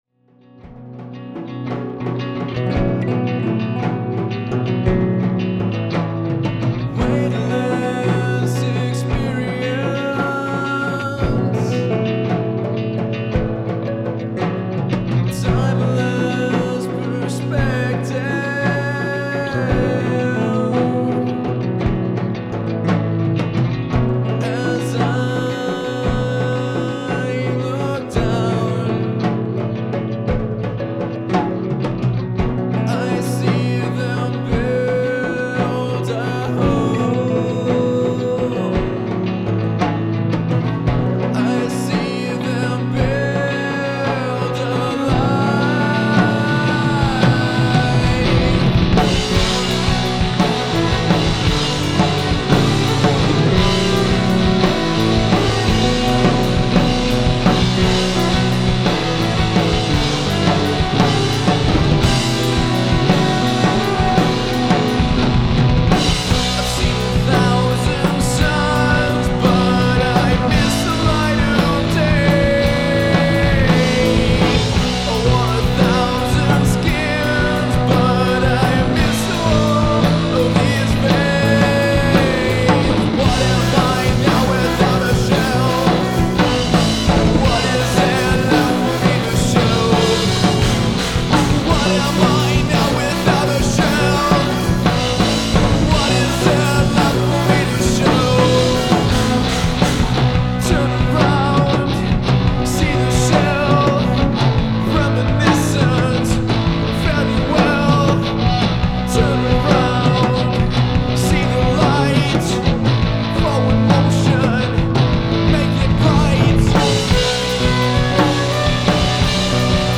progressive music from Colorado Springs